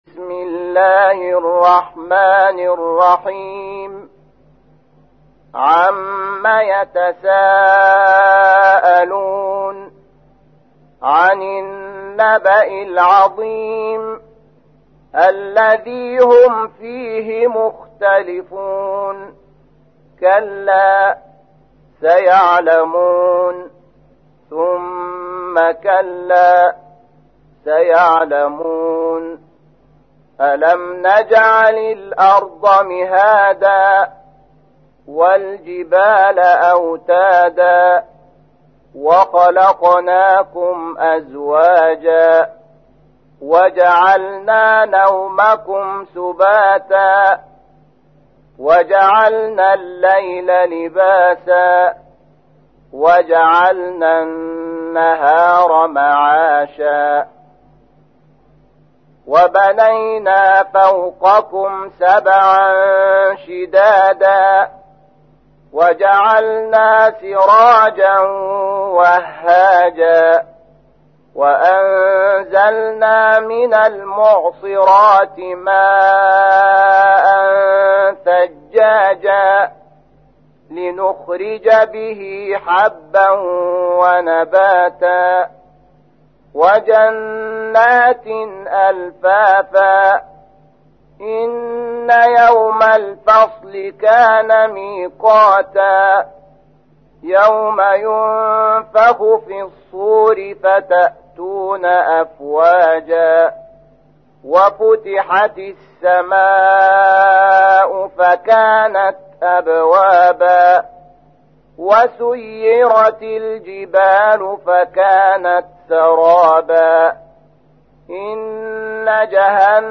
تحميل : 78. سورة النبأ / القارئ شحات محمد انور / القرآن الكريم / موقع يا حسين